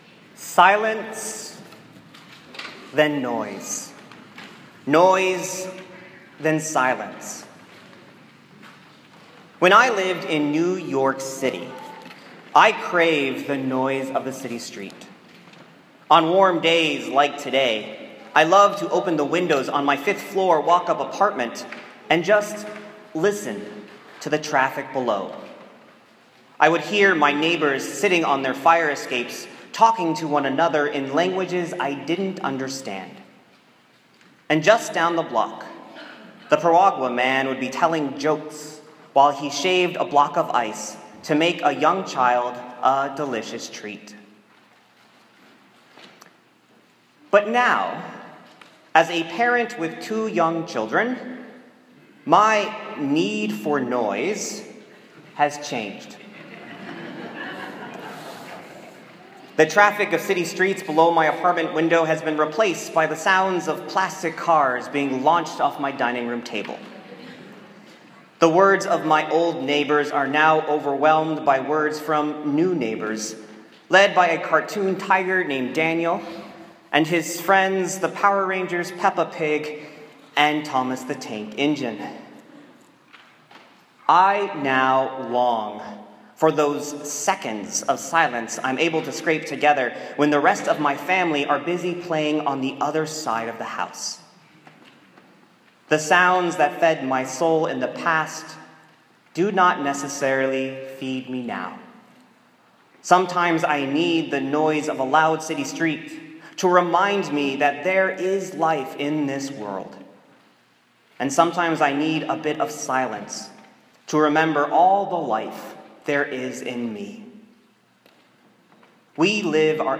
Ground Goes Boom: A sermon for Easter Morning.